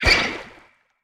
Sfx_creature_babypenguin_flinch_swim_01.ogg